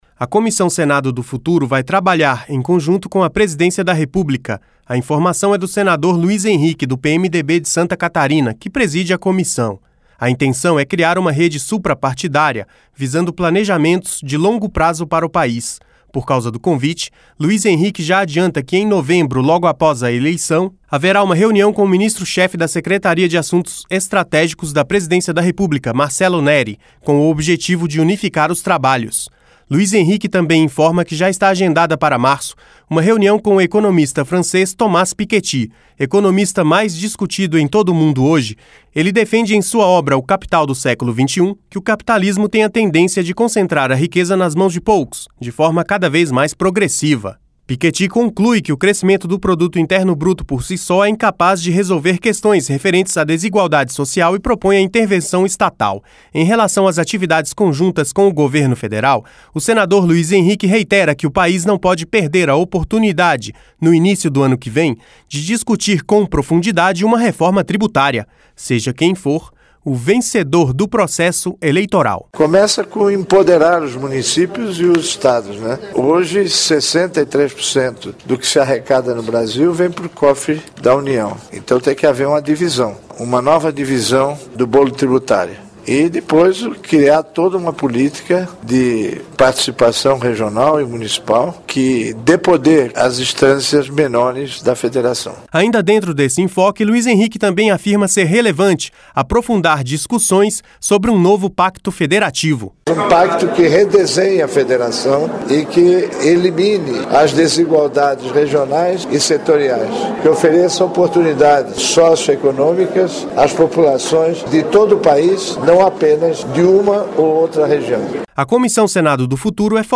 (Repórter) A Comissão Senado do Futuro vai trabalhar em conjunto com a Presidência da República.